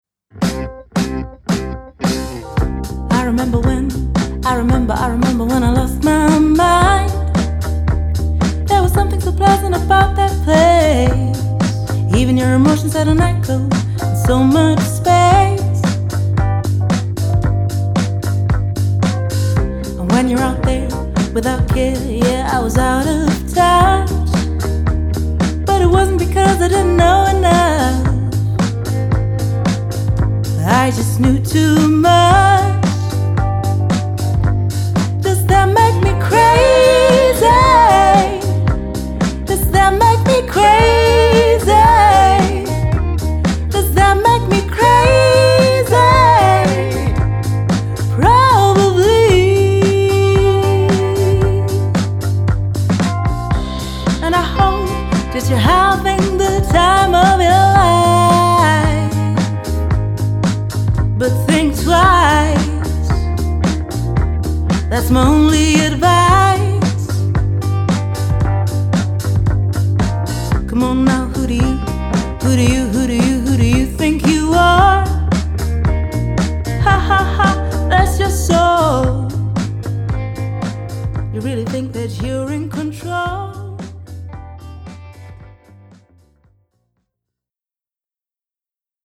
Sie klingt zugleich freundlich hell und auch samtig dunkel.